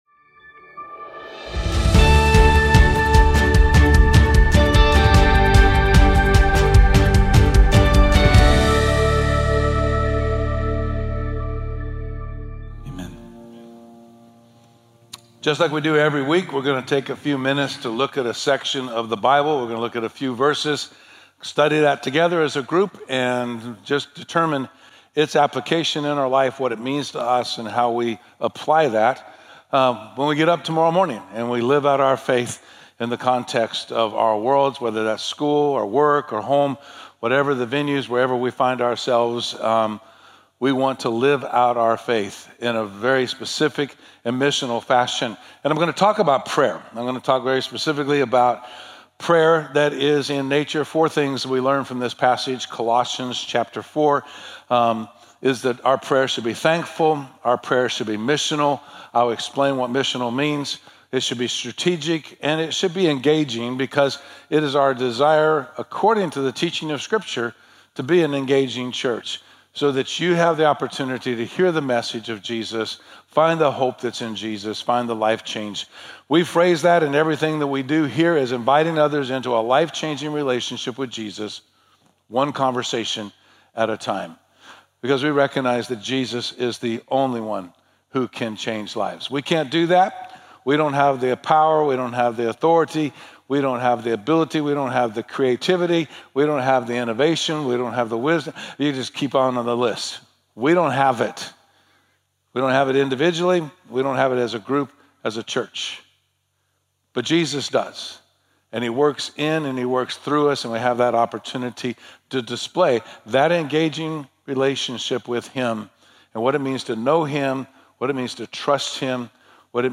january-19th-sermon.mp3